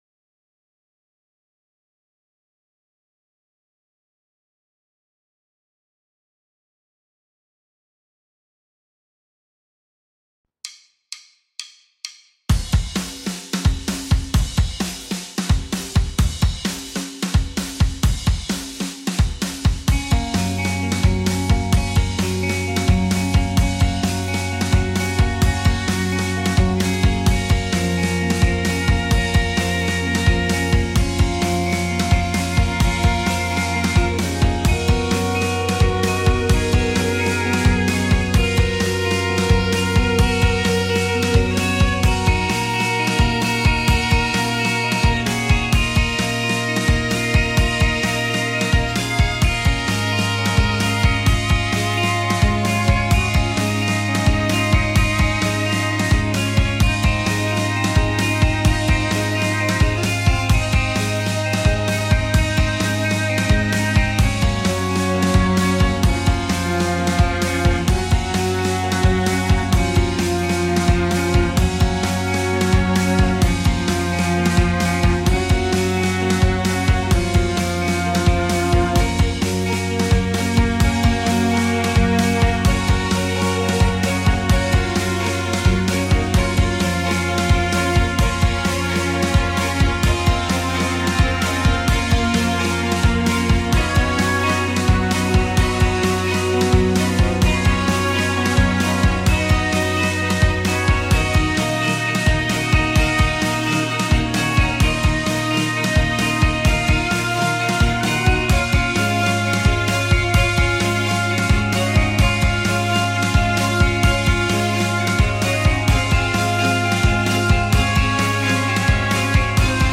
symphonic post-rock